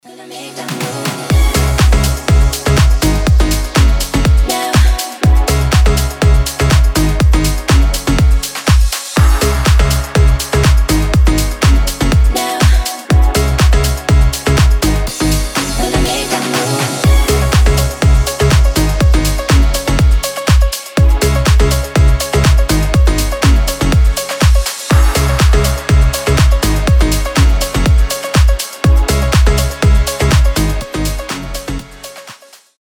• Качество: 320, Stereo
заводные
house
piano house
Заводной органик/пьяно хаус